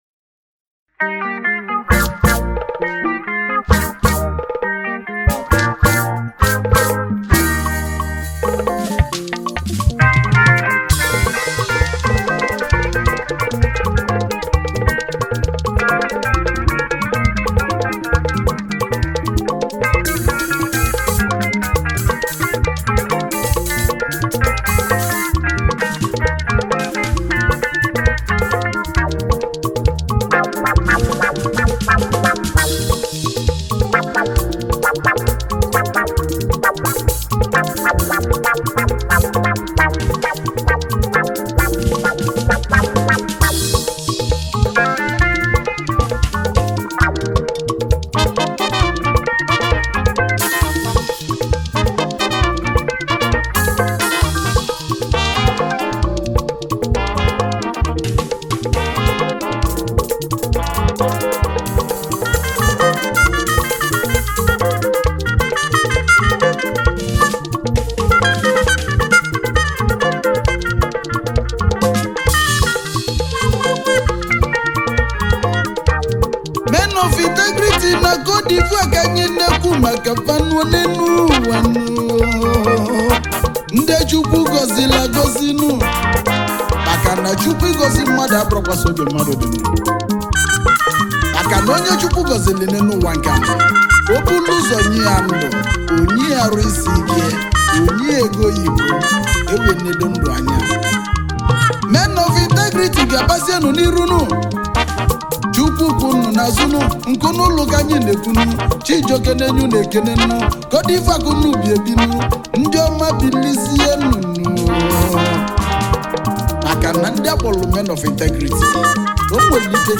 February 28, 2025 Publisher 01 Gospel 0